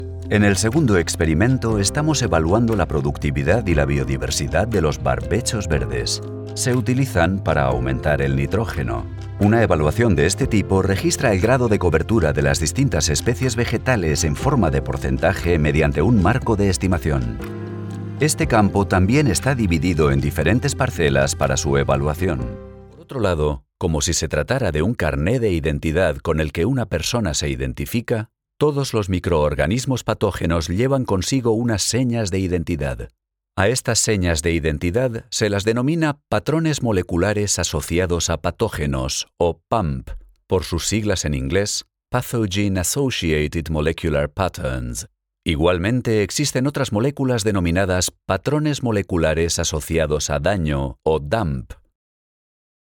Deep, Distinctive, Versatile, Reliable, Warm
Explainer